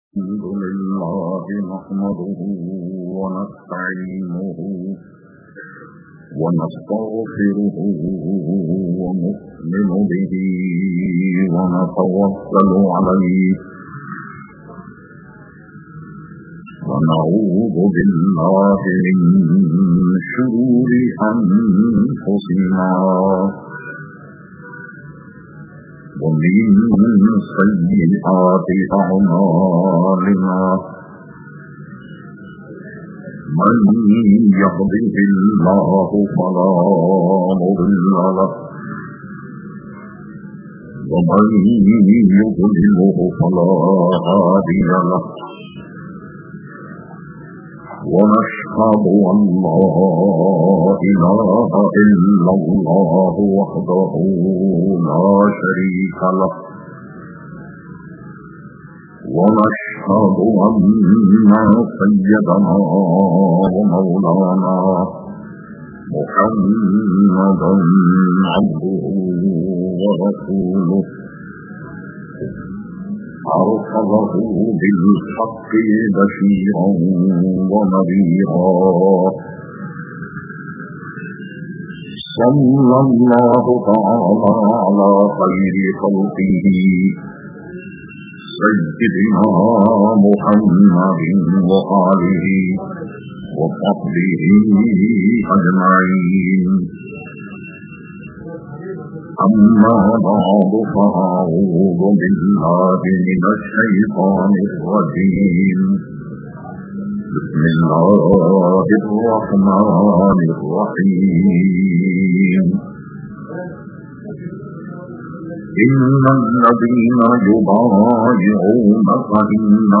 Category : Speeches | Language : Urdu